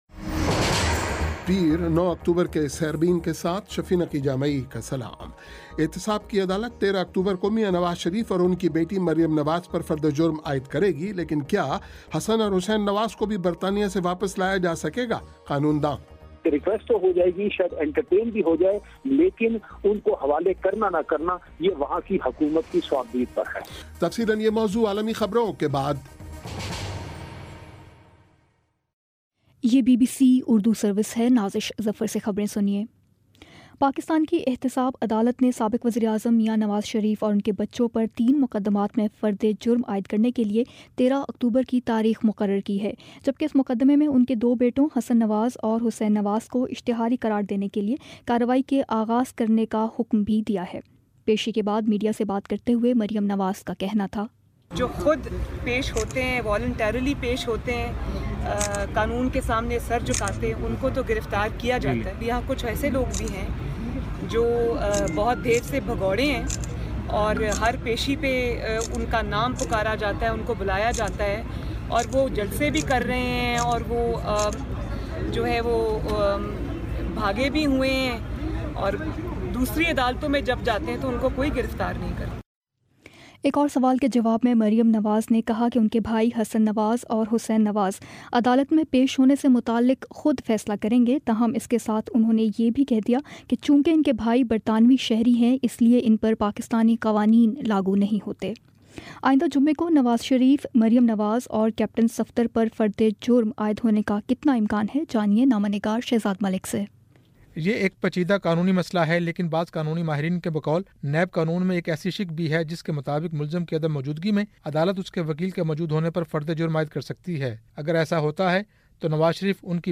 پیر 09 اکتوبر کا سیربین ریڈیو پروگرام